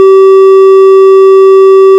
• Testi raames käivitatakse kõikides Eesti sireenipostides (121 posti) lühike n-ö piiksuga
• Helifail on valitud sääraselt, et see ei segaks inimeste igapäevaelu, kuid oleks sellegipoolest kuuldav.
Hetketesti raames käivitub sireen piiksuheliga, mis kestab kaks sekundit.